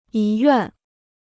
遗愿 (yí yuàn): Letzte Wünsche; Testament.